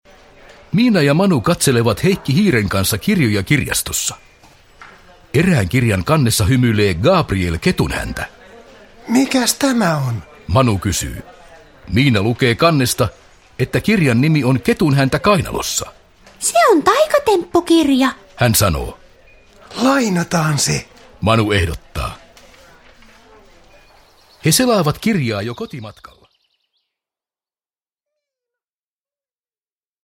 Miina ja Manu Routa porsaan kotiin ajaa – Ljudbok – Laddas ner